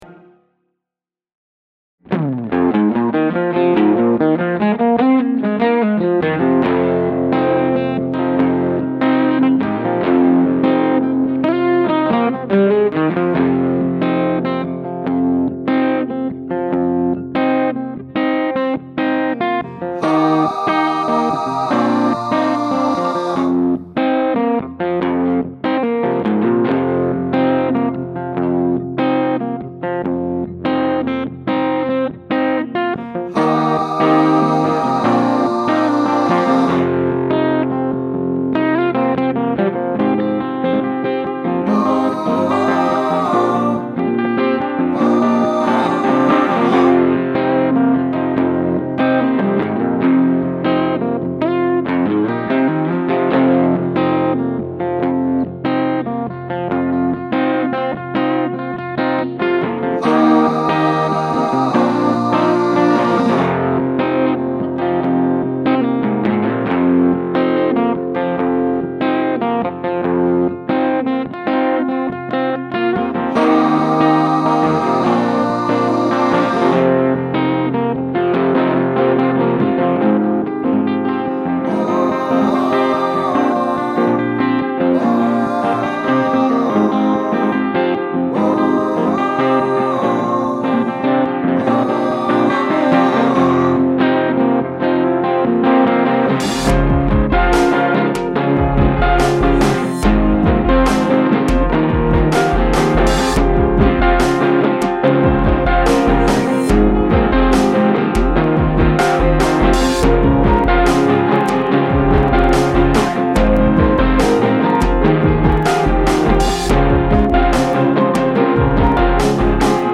The adventures of a bassist in musiclandia
It's nothing horribly fancy - Bm, F#m, A, E. Etc. But, as I can often do, within the span of roughly 15 minutes I had the outline for a song.
After that comes the typical drop down to soft before the build to the last chorus...
It's not often I write something kinda upbeat.
It's probably a little more radio-friendly than a majority of my stuff.